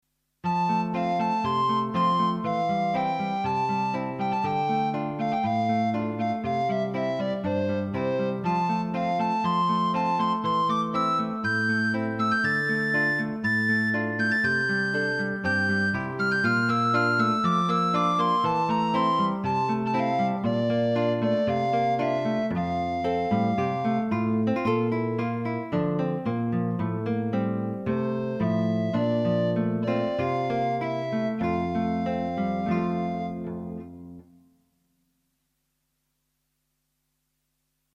Hierbij een aantal fragmenten op basis van inheemse en folkloristische instrumenten.
In Retrospect Nederlands 0:38 596kb 1985 Lief, kinderlijk wijsje met gitaar en blokfluit